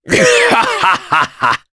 Gau-Vox_Happy3_jp.wav